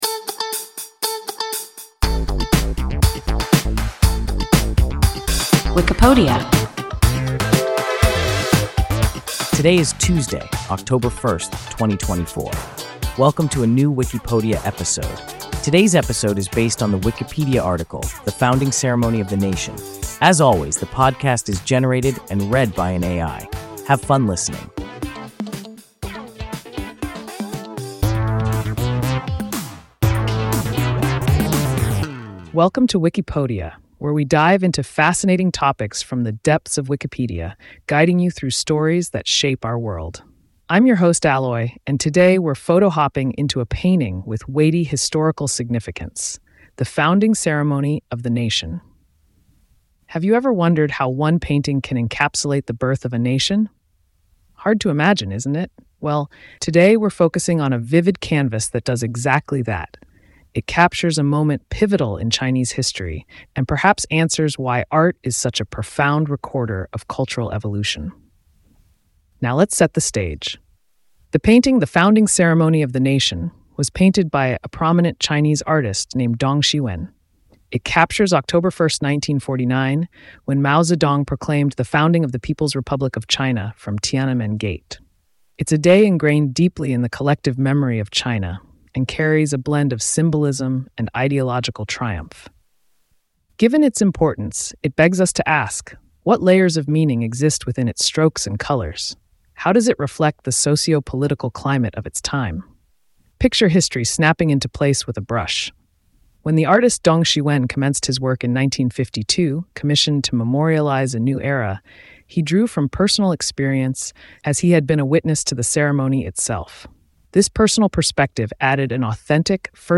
The Founding Ceremony of the Nation – WIKIPODIA – ein KI Podcast